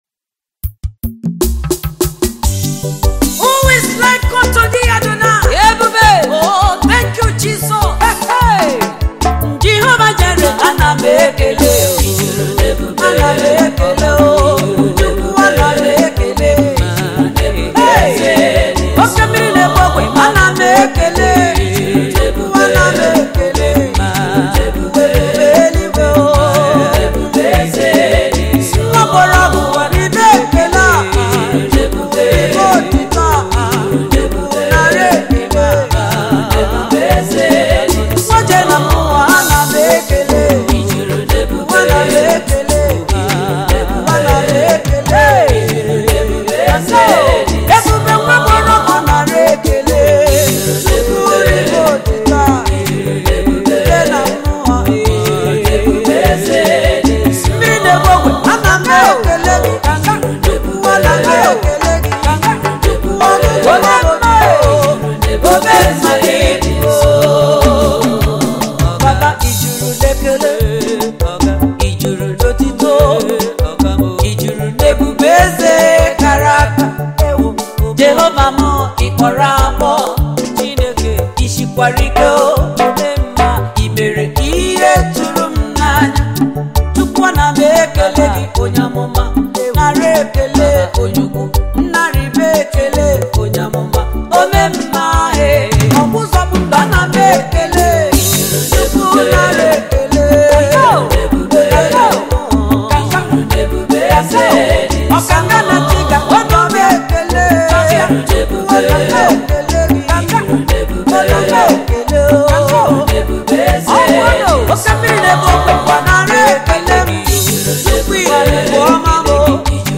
Igbo Gospel music
worship single
September 9, 2024 admin Gospel, Music 0